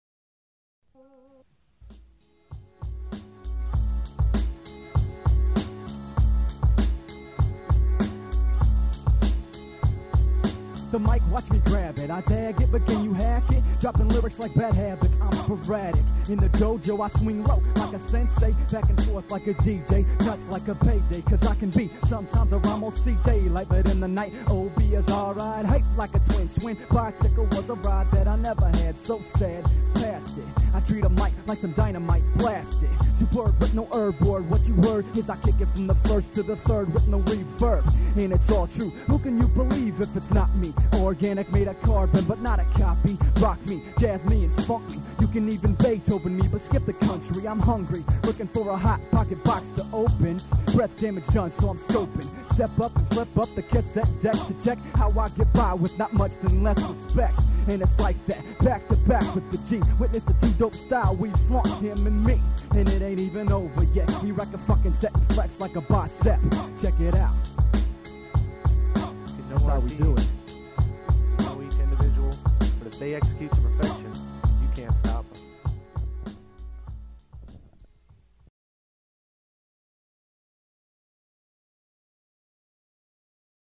Vocal chords display post-football game damage.